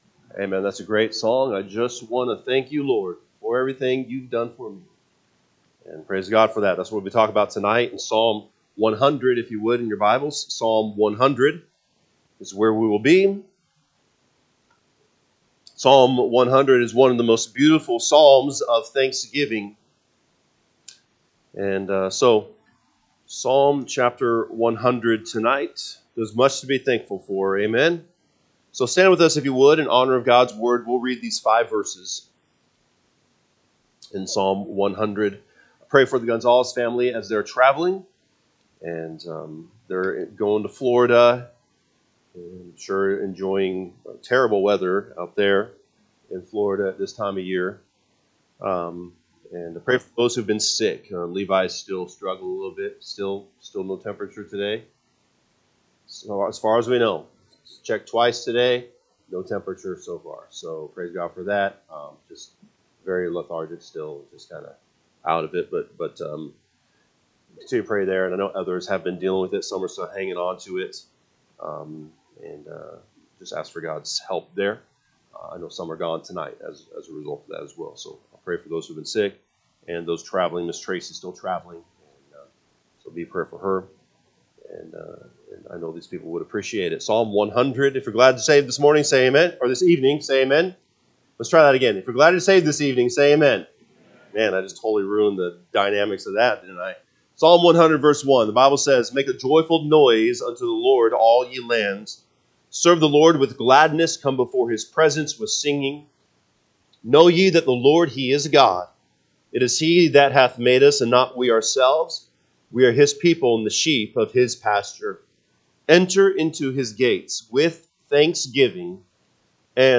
November 24, 2024 pm Service Psalm 100 (KJB) 100 Make a joyful noise unto the Lord, all ye lands. 2 Serve the Lord with gladness: come before his presence with singing. 3&n…